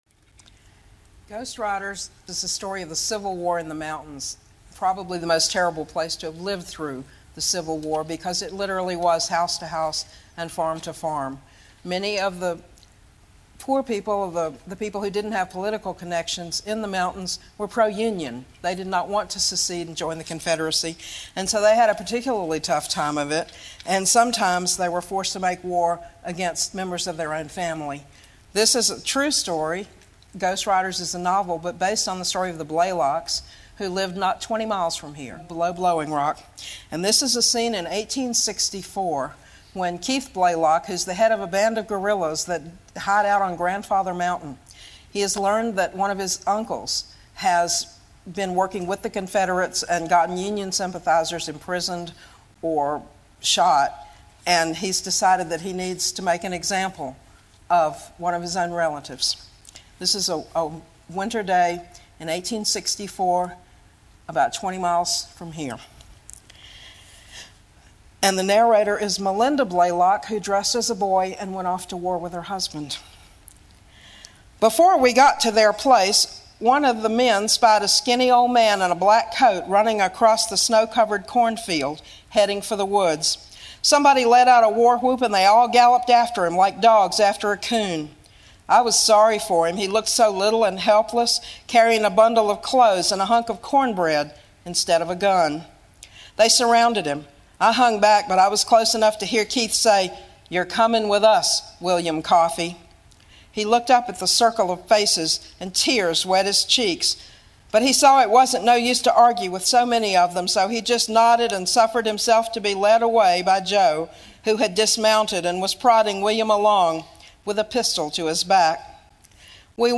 Hear Sharyn read from Ghost Riders :
gr_reading.mp3